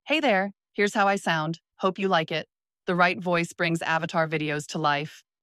Sophia - Narration - Friendly 😊
🌍 Multilingual👩 Женский
Пол: female
Этот скрипт отображает тайские голоса и голоса с поддержкой множественных языков из HeyGen API с возможностью фильтрации.